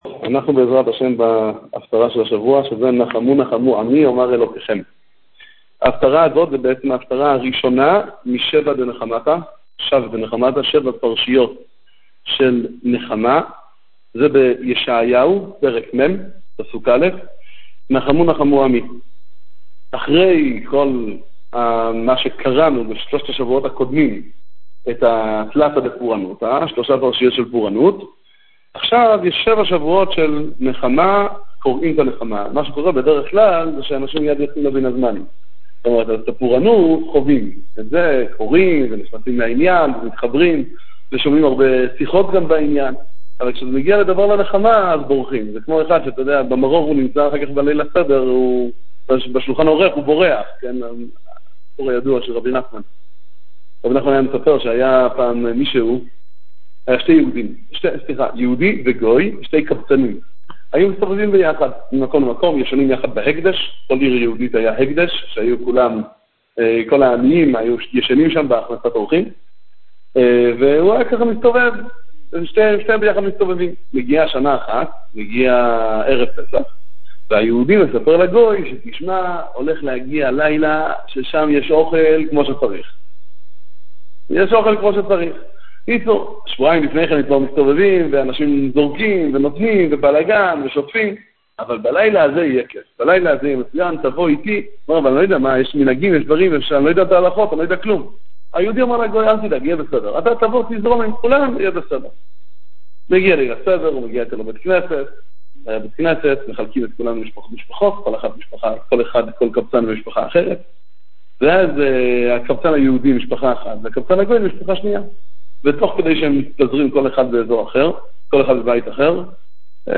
נחמו נחמו עמי - הפטרת פרשת ואתחנן - ביהמ"ד משאת מרדכי רמב"ש א'
שיעור תורה על הפטרת ואתחנן, דברי חיזוק ונחמה